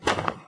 pedology_silt_footstep.2.ogg